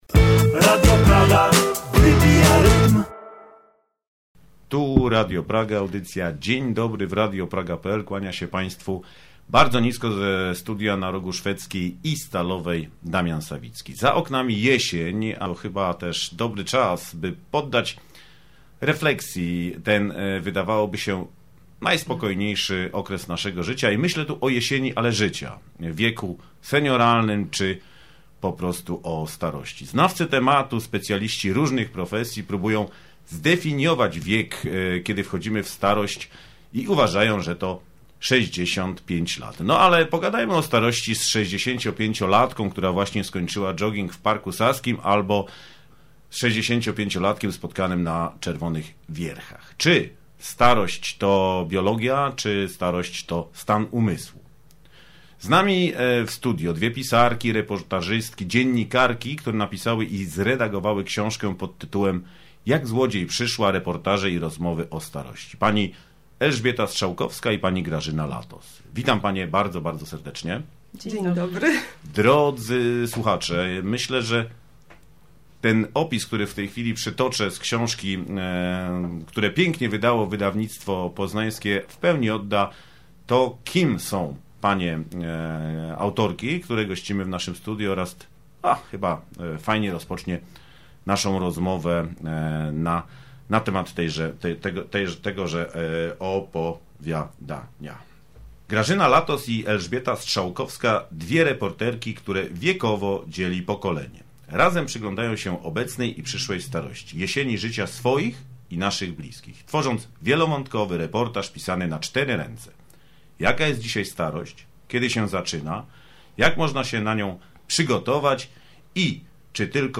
Dziś publikujemy zapis części pierwszej naszej rozmowy.